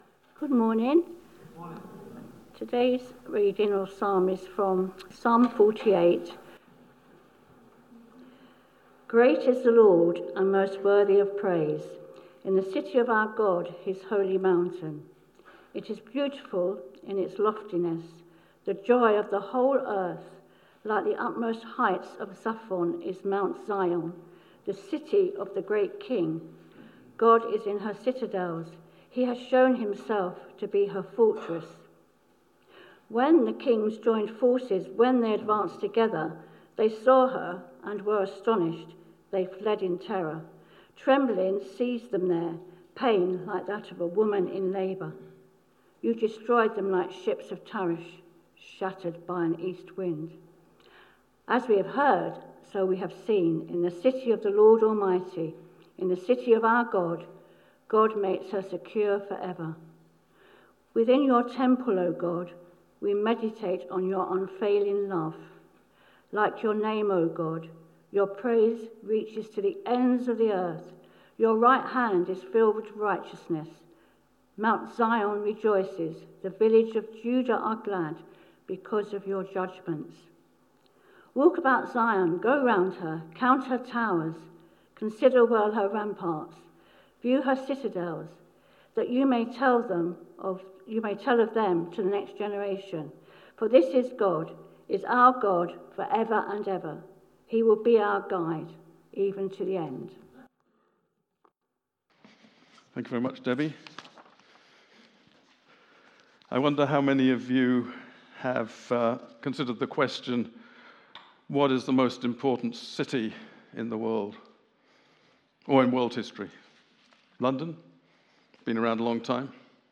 Media for Sunday Service on Sun 03rd Aug 2025 10:00
Passage: Psalm 48 Series: Summer of Psalms Theme: Sermon